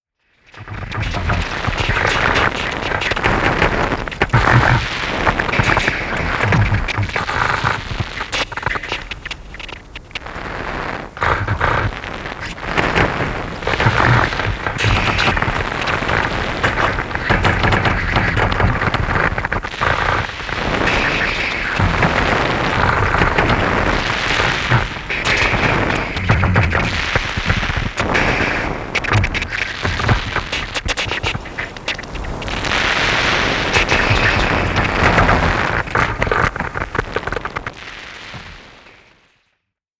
Sound Art Series